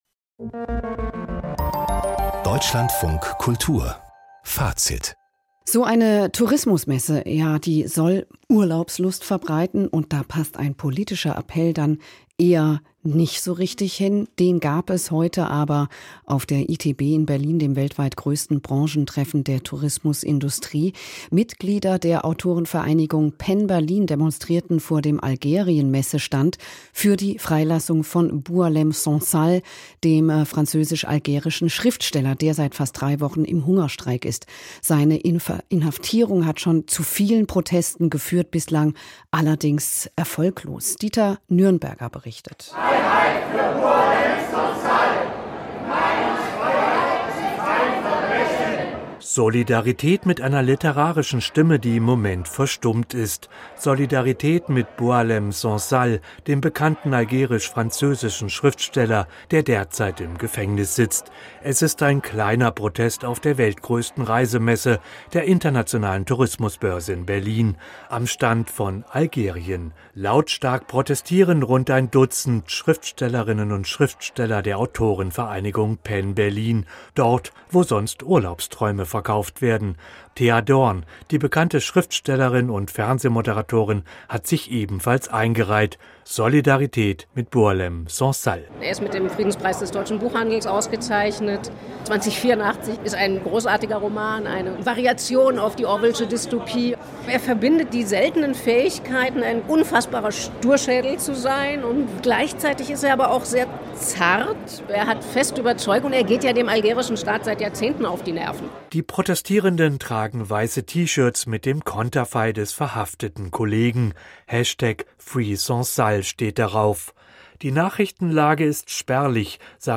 DLF Kultur, Bericht